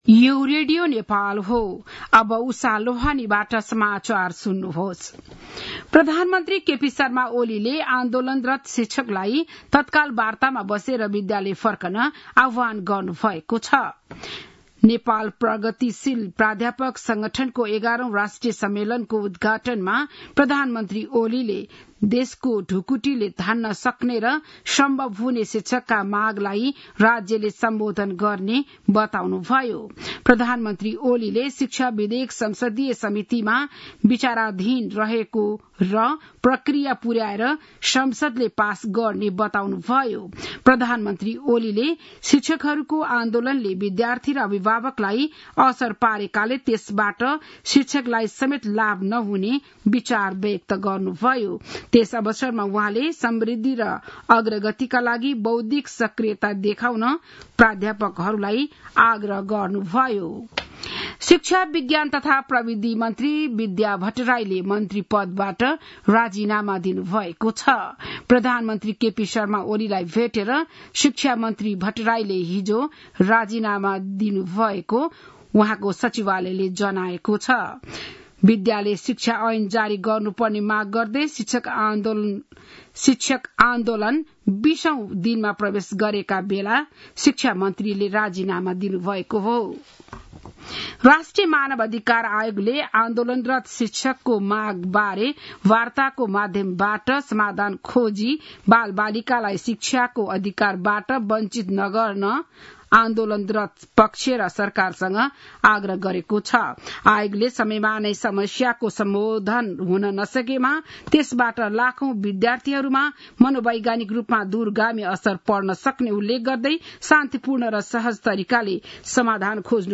बिहान ११ बजेको नेपाली समाचार : ९ वैशाख , २०८२